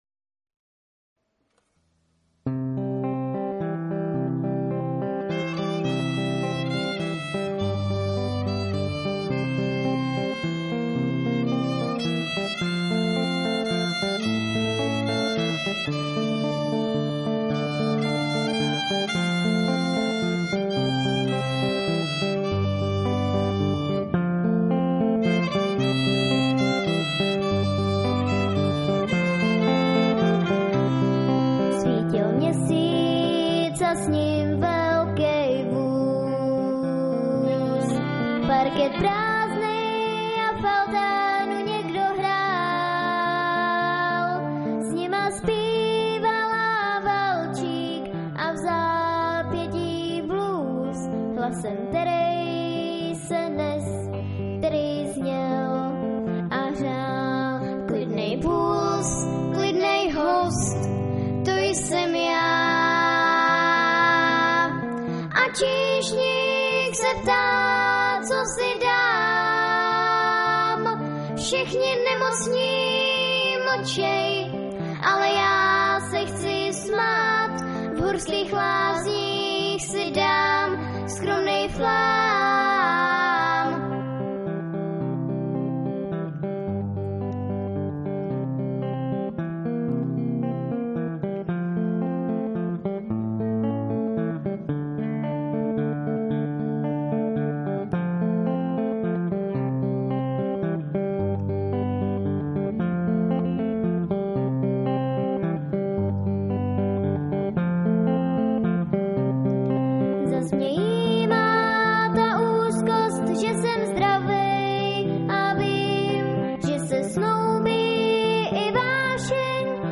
V loňském roce na táborské Bambiriádě 2006 opět vystoupili vítězové Dětské porty z Českého Krumlova - duo